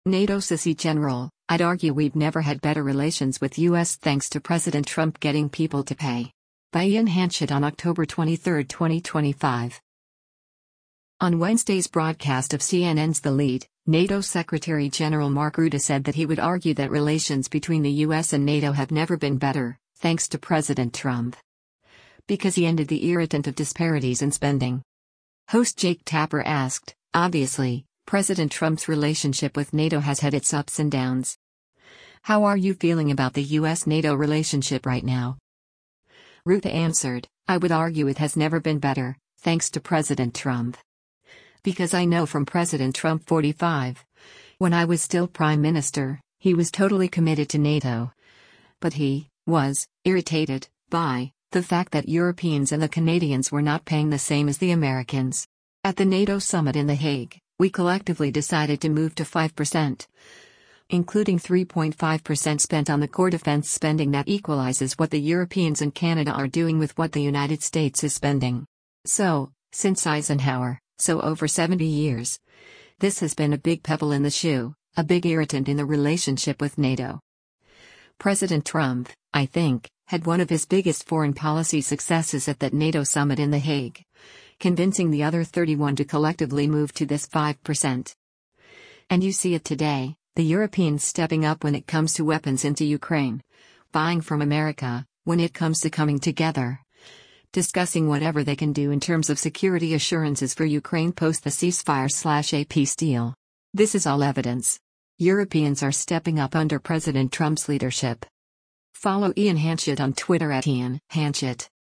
On Wednesday’s broadcast of CNN’s “The Lead,” NATO Secretary General Mark Rutte said that he would argue that relations between the U.S. and NATO have “never been better, thanks to President Trump.”
Host Jake Tapper asked, “Obviously, President Trump’s relationship with NATO has had its ups and downs. How are you feeling about the U.S.-NATO relationship right now?”